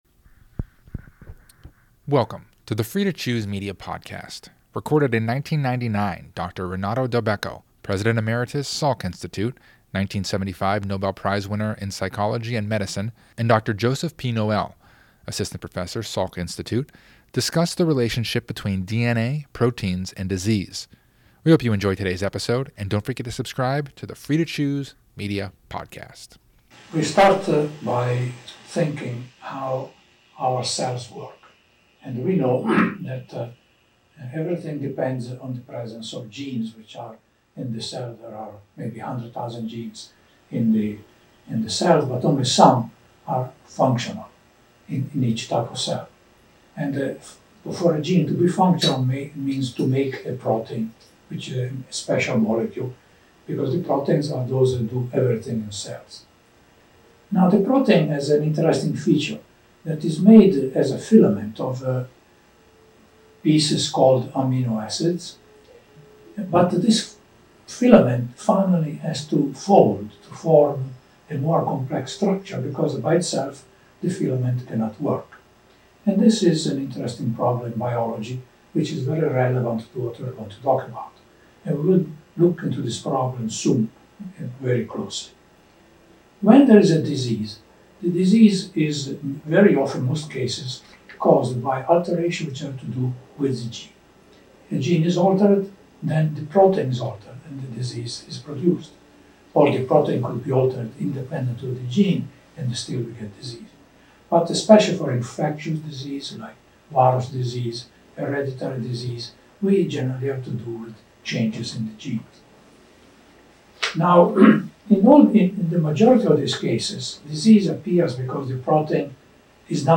Recorded in 1999, Dr. Renato Dulbecco, President Emeritus, Salk Institute, 1975 Nobel Prize in Physiology and Medicine